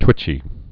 (twĭchē)